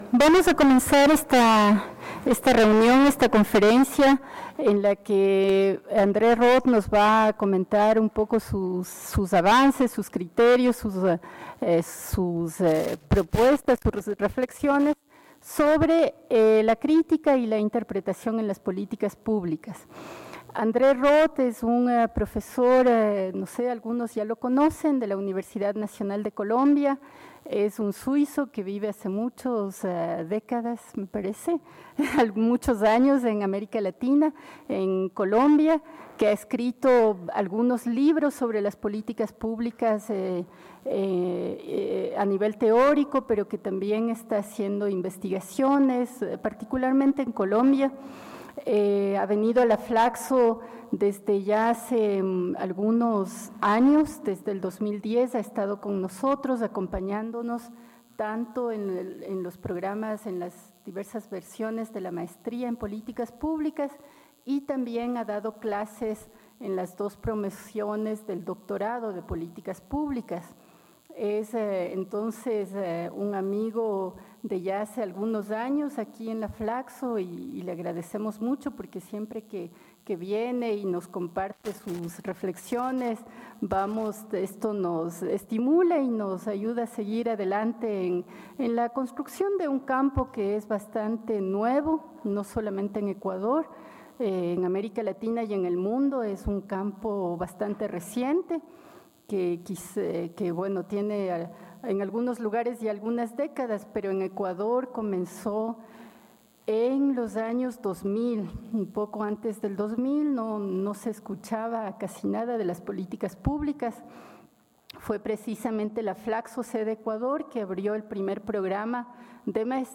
Coloquio de investigación